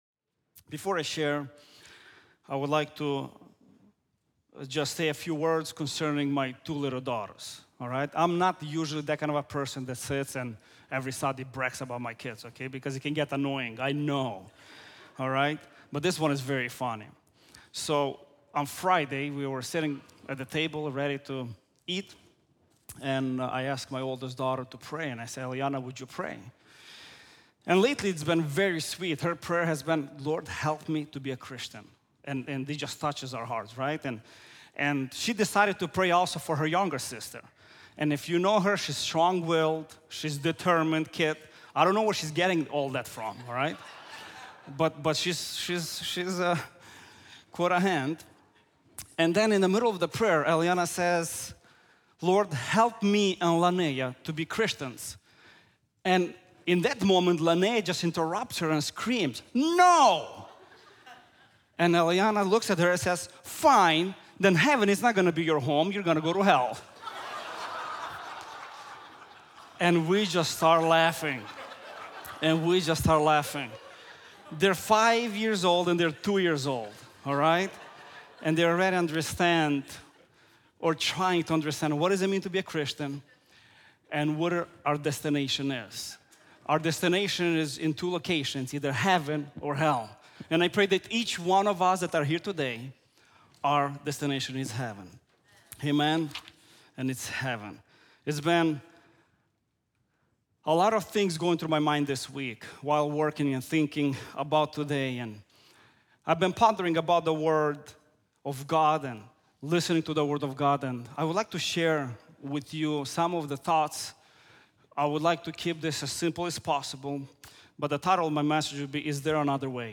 Is There Another Way? | Times Square Church Sermons